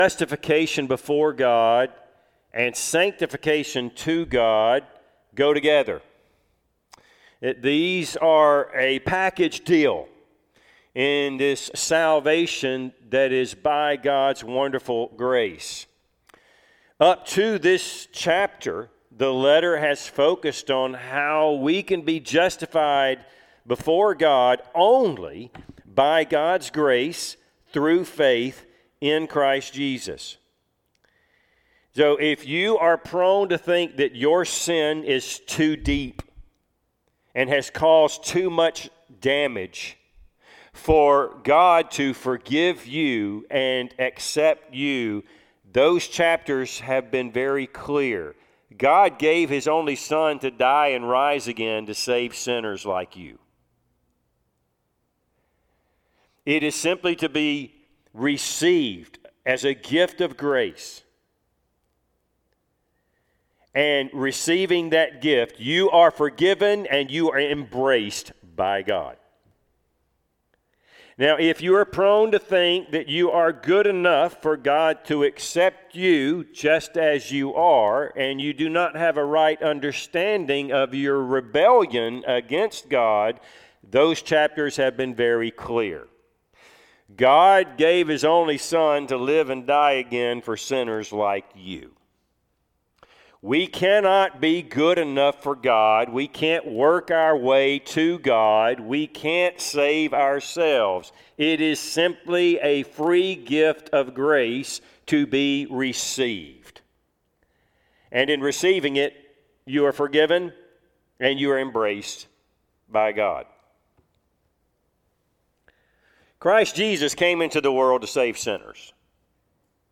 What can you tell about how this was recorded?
Service Type: Sunday AM Topics: Christian living , Sanctification